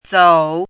chinese-voice - 汉字语音库
zou3.mp3